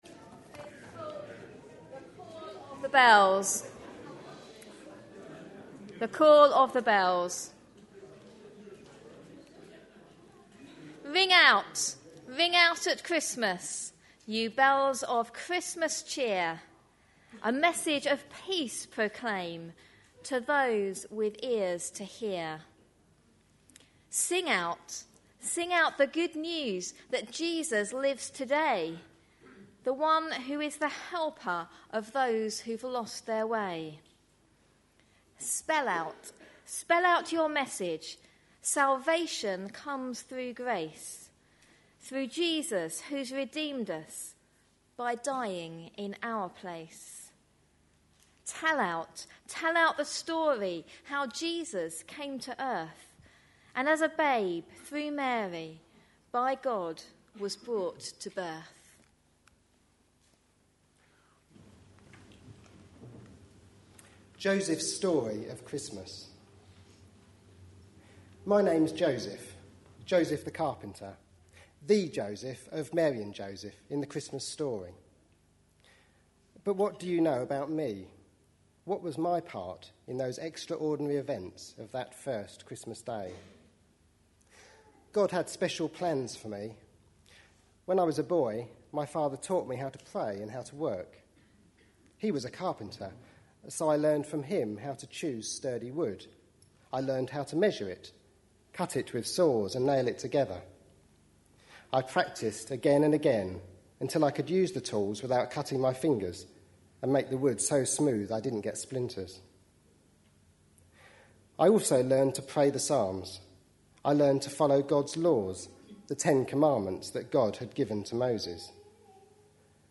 A sermon preached on 19th December, 2010.